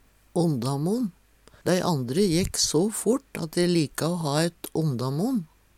ondamon - Numedalsmål (en-US)